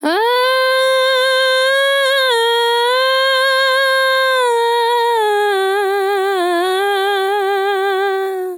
TEN VOCAL FILL 23 Sample
Categories: Vocals Tags: dry, english, female, fill, sample, TEN VOCAL FILL, Tension